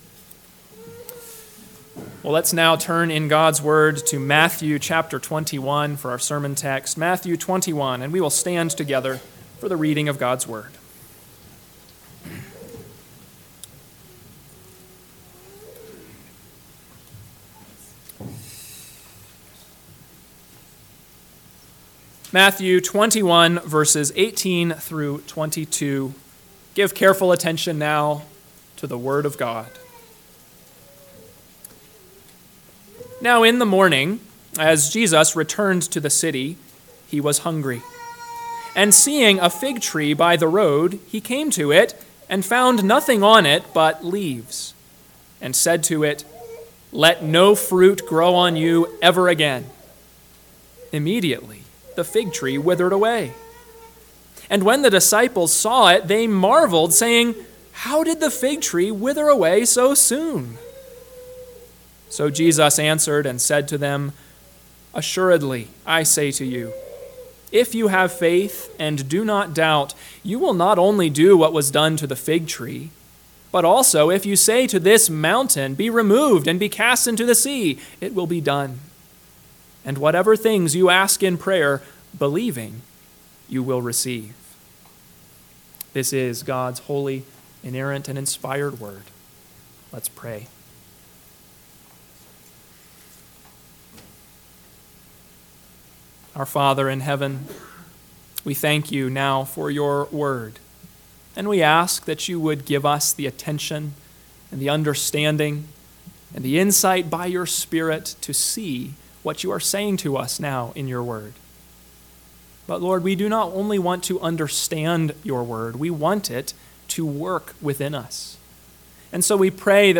AM Sermon – 9/1/2024 – Matthew 21:18-22 – Northwoods Sermons